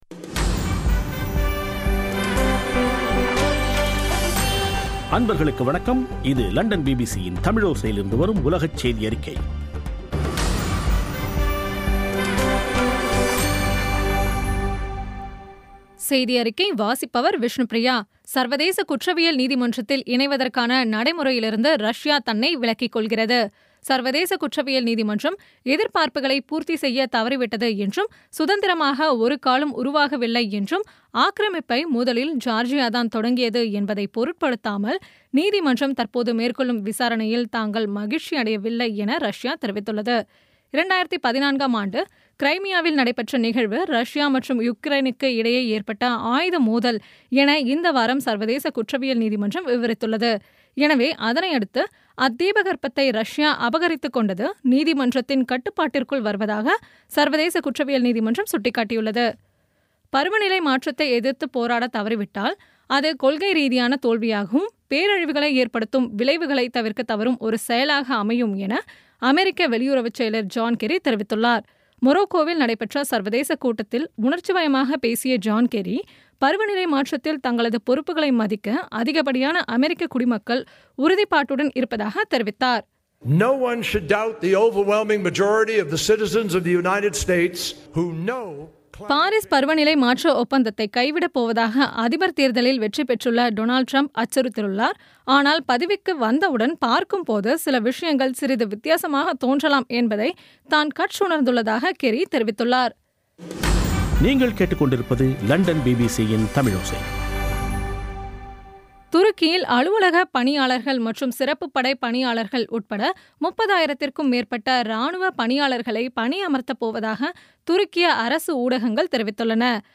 பி பி சி தமிழோசை செய்தியறிக்கை (16/11/2016)